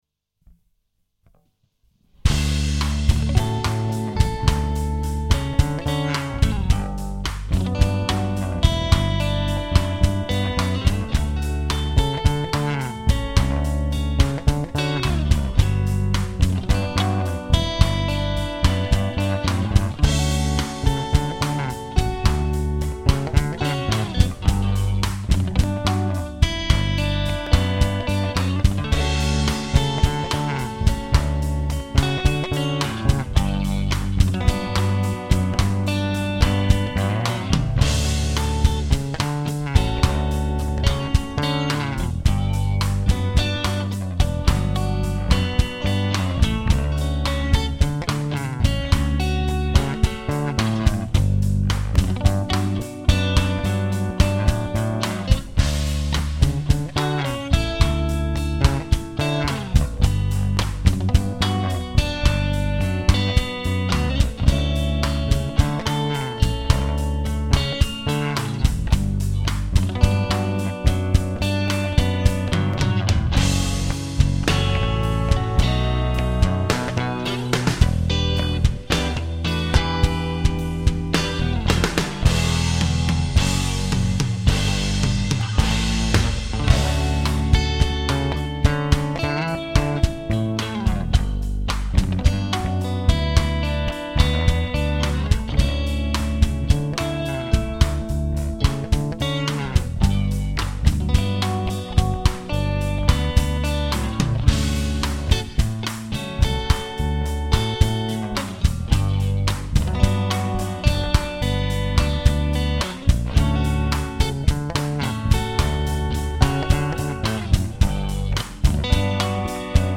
coud-u-ohne-Keyboard-playback-01.mp3